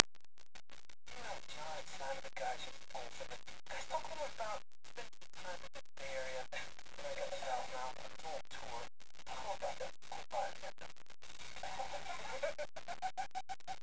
This is Carson making imitating Matt "Mr. Troll" Pinfield.